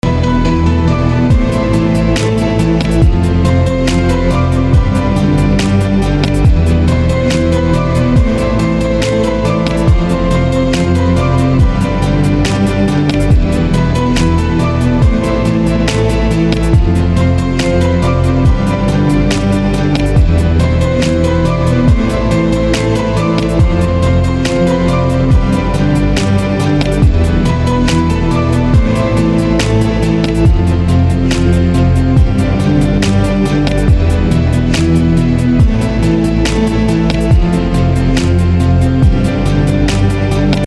• Качество: 320, Stereo
спокойные
без слов
инструментальные
пианино
нежные
Красивая инструментальная версия знаменитой композиции